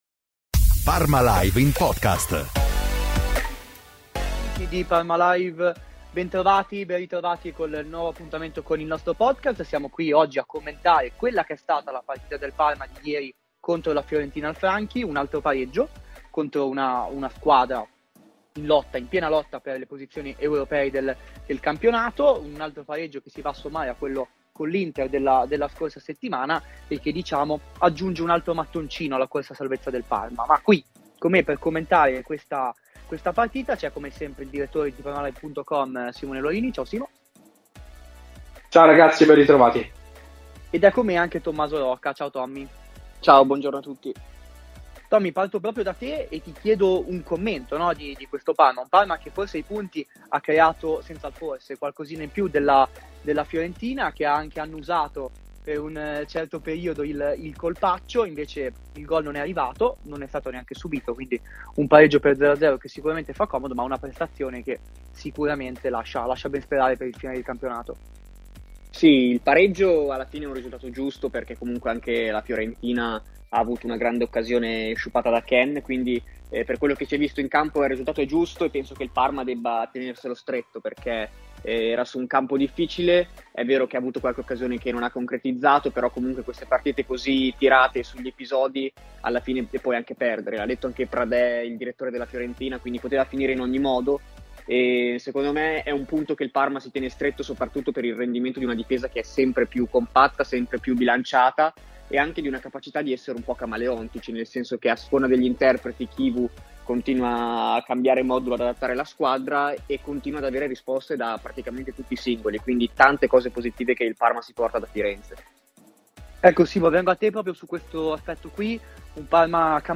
Nel segno di Cherubini Nella puntata di oggi focus sul pareggio del Parma sul campo della Fiorentina, il quinto segno X di fila dei crociati di Cristian Chivu, che ora si preparano alla sfida alla Juventus, lunedì al Tardini. Proprio sui bianconeri ci si concentra nella seconda parte della puntata, con un giornalista che segue la Juventus che racconta il momento della squadra di Tudor e racconta anche il Cherubini dirigente, che da qualche mese lavora a Parma dopo una lunga avventura a Torino.